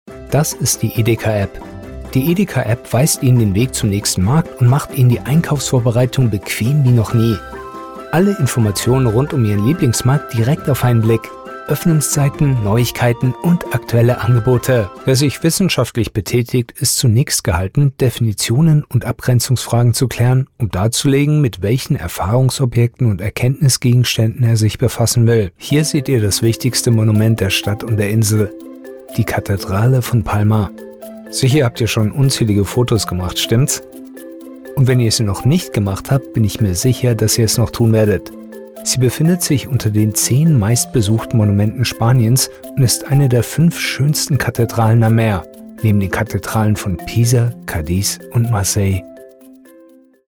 Explainers
authoritative, confident, foreign-language, german, informative, neutral, professional, Straight Forward